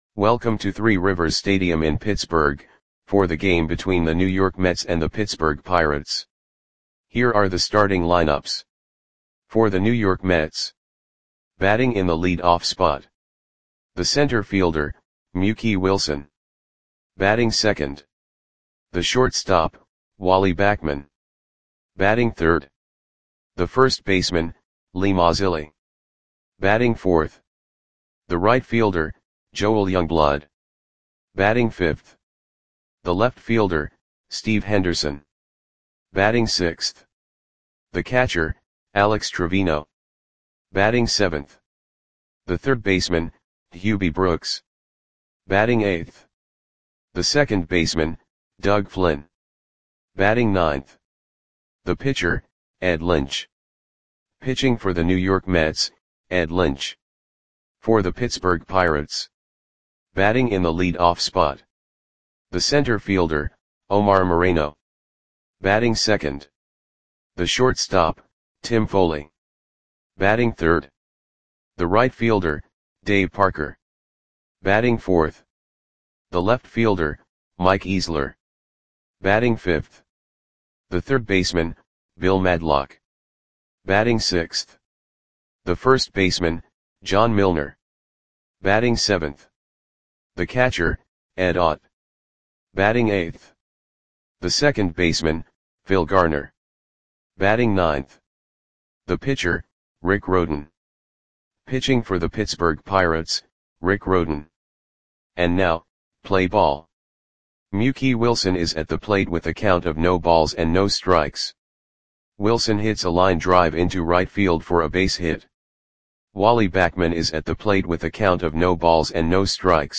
Audio Play-by-Play for Pittsburgh Pirates on September 19, 1980
Click the button below to listen to the audio play-by-play.